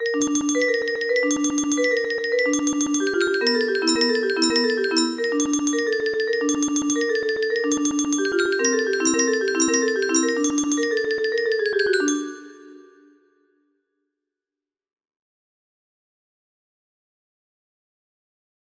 Las cuatro estaciones (El Verano) music box melody